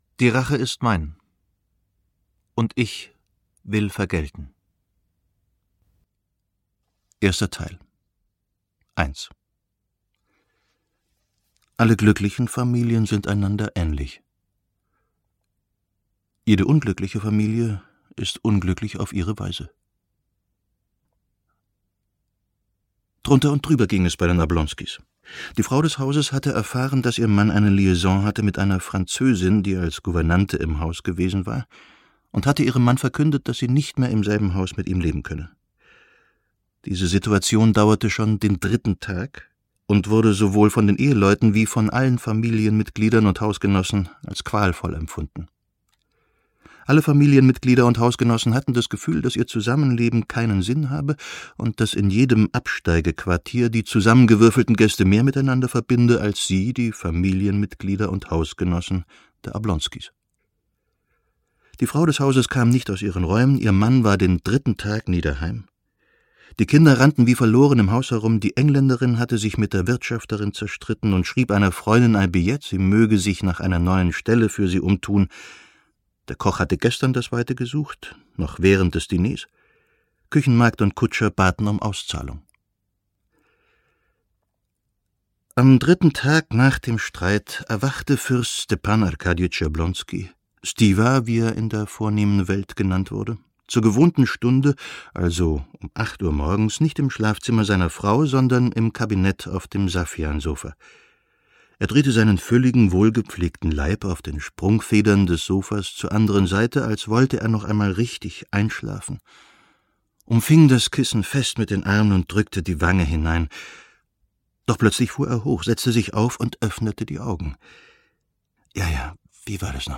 Ulrich Noethen (Sprecher)